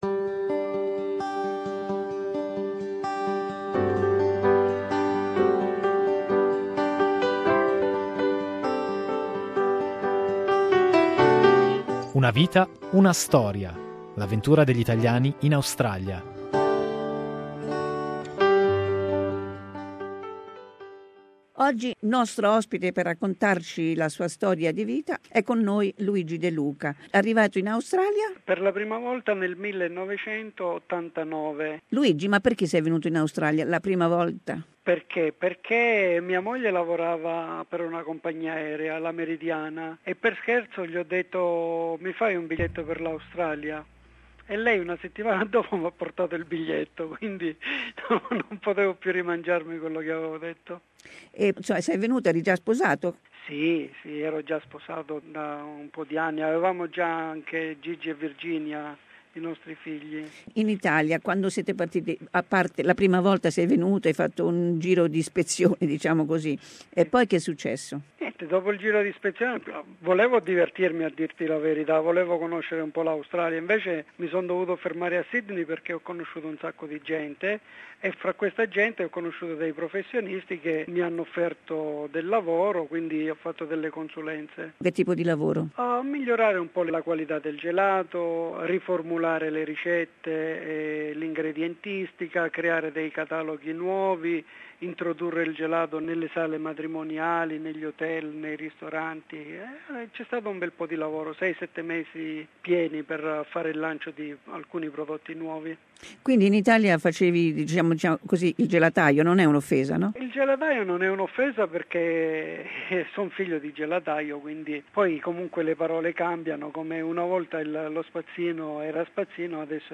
Part 1 of our interview